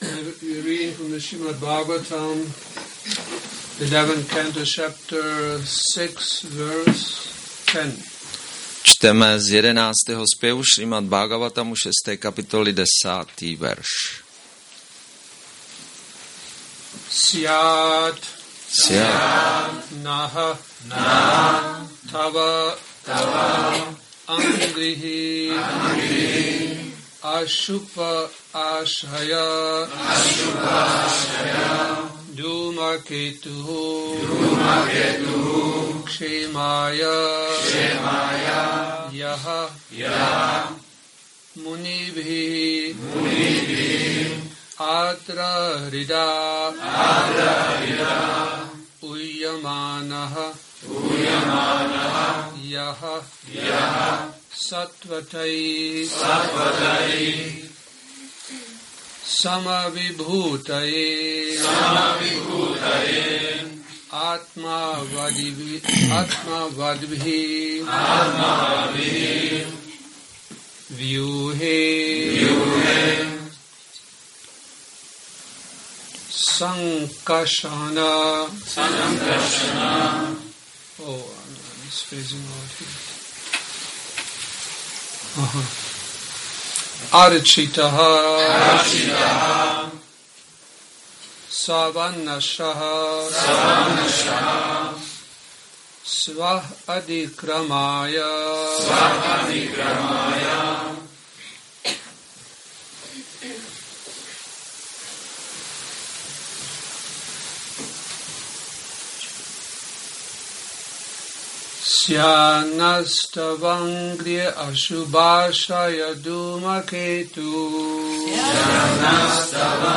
Přednáška SB-11.6.10 – Šrí Šrí Nitái Navadvípačandra mandir